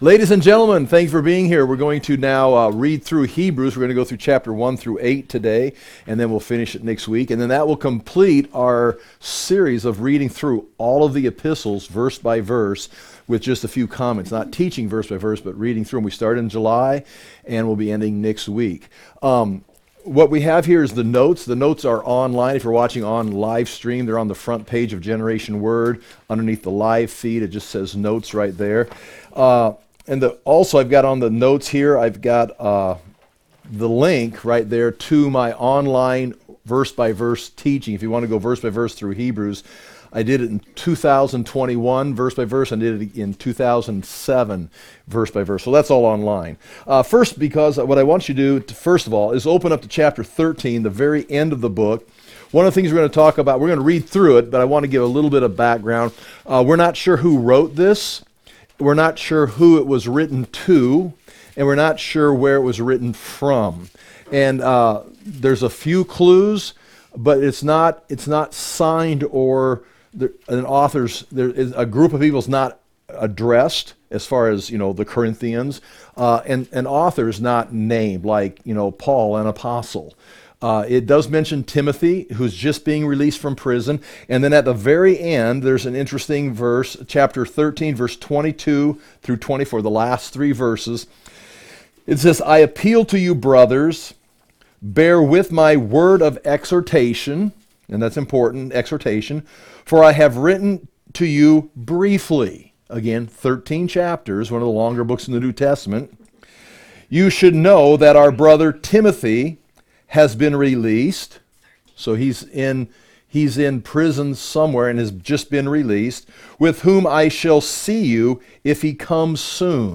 We did this series in six months reading through all of the Epistles aload on livestream between July-December of 2025.